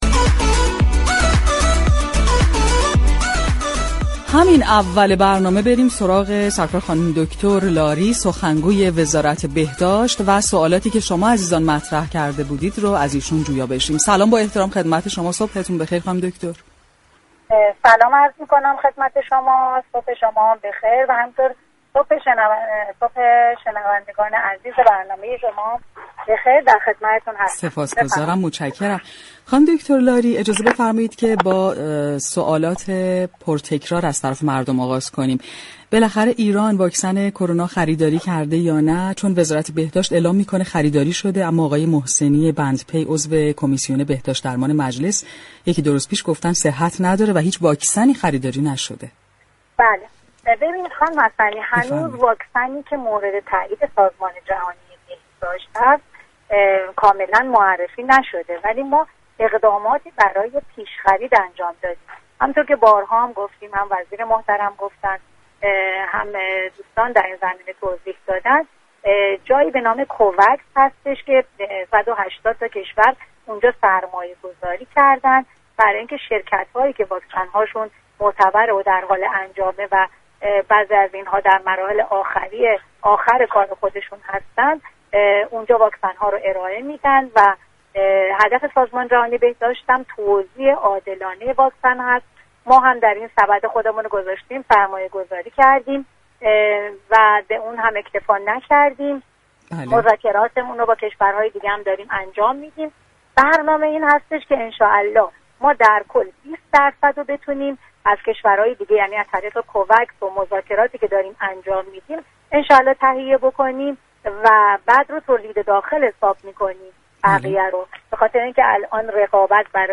در برنامه تهران كلینیك رادیو تهران